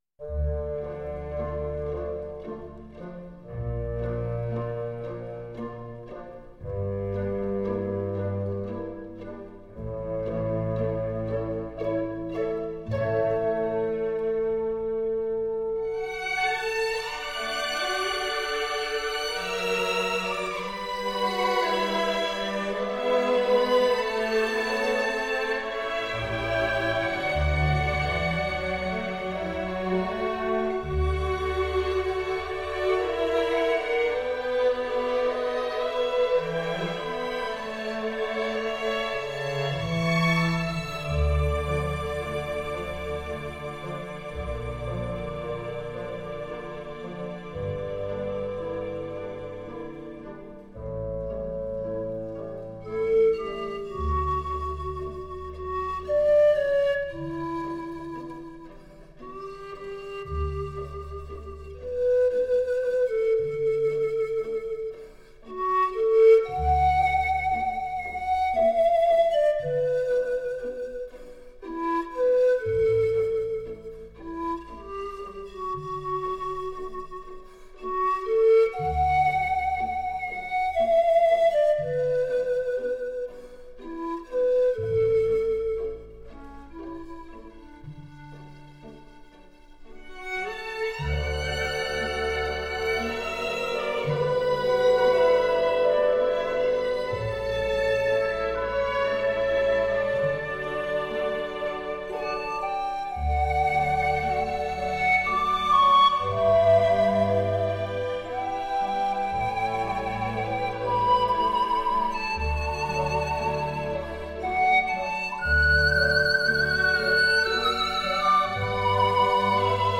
他的演奏音色纯净秀美，醇厚圆润，表现十分细腻，气息控制功力尤深。